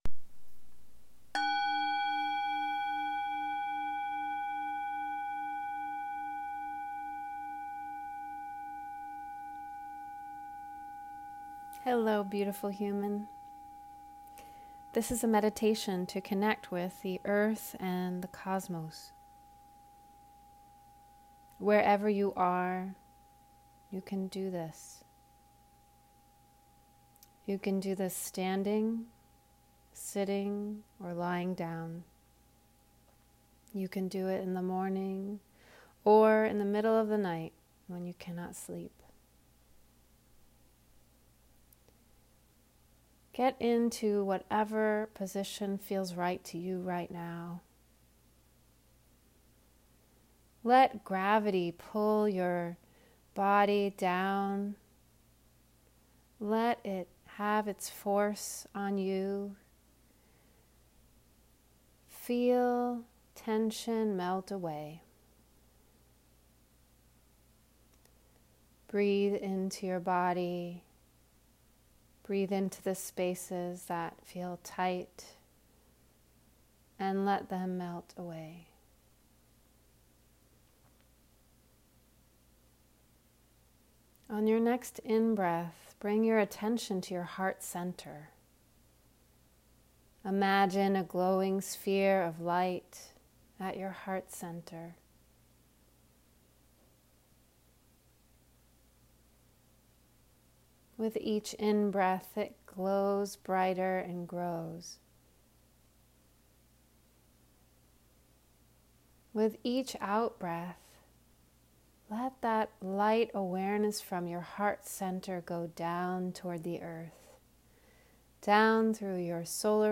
A ten-minute meditation to help you connect with the truth that you are a conduit between the Earth and the Sky. Tap into the wisdom that our ancestors knew, that indigenous people have known all along.